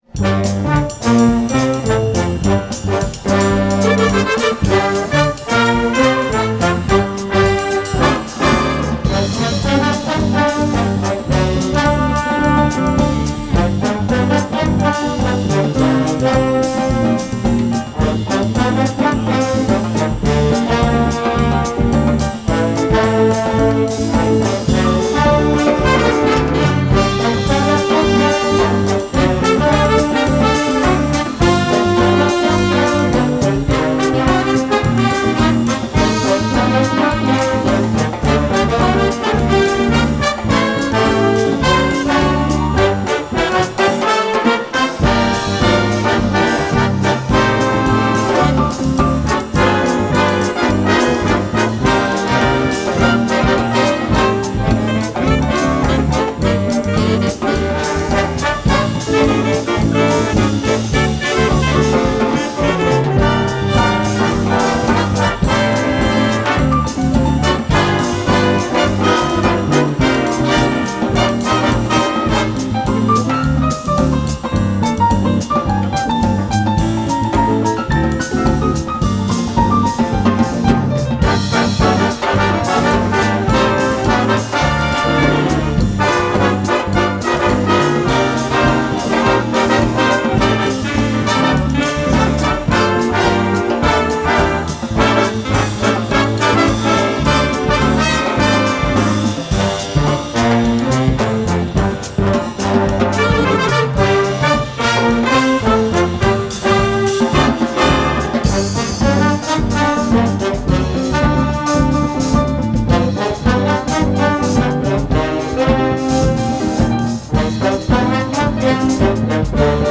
Live im Seehotel Neufeld